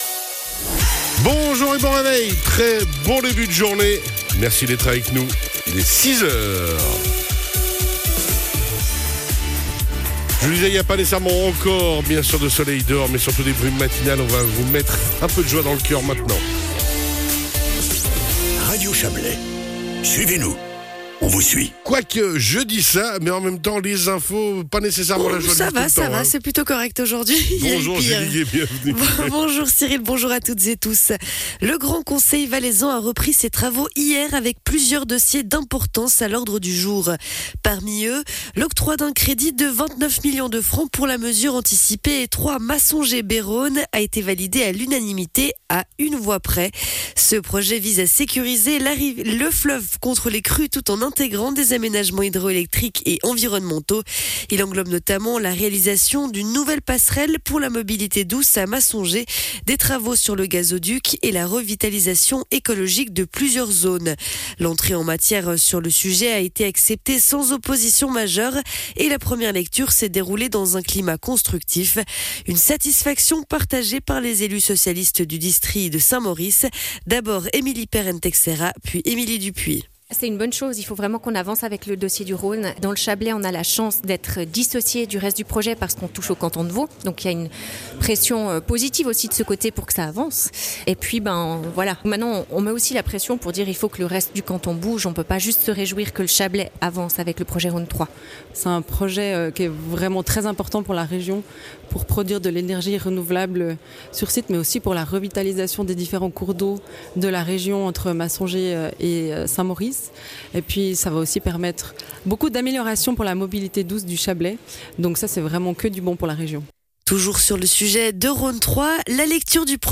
Le journal de 6h00 du 12.02.2025